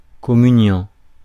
Ääntäminen
Ääntäminen France: IPA: [kɔ.my.njɑ̃] Haettu sana löytyi näillä lähdekielillä: ranska Käännös Substantiivit 1. konfirmand 2. kommunikant 3. nattvardsgäst Suku: m .